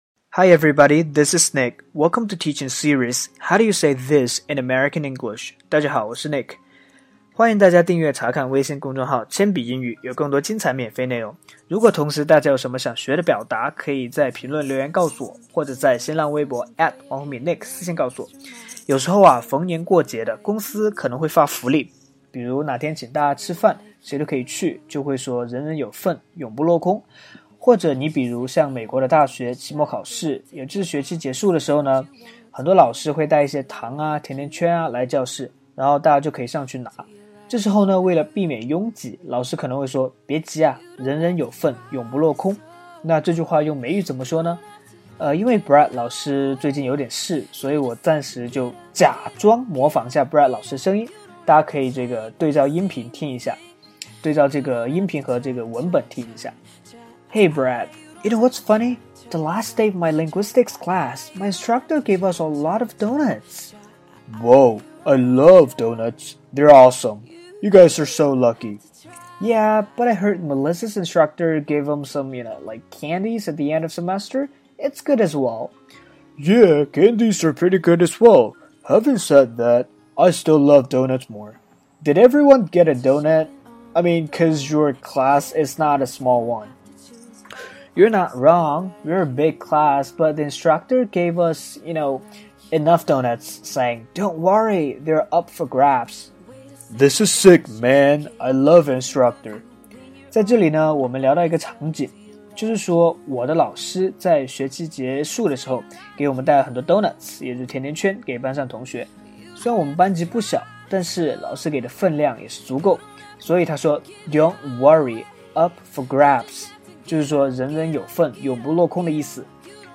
在线英语听力室全网最酷美语怎么说:第49期 人人有份的听力文件下载, 《全网最酷美语怎么说》栏目是一档中外教日播教学节目，致力于帮大家解决“就在嘴边却出不出口”的难题，摆脱中式英语，学习最IN最地道的表达。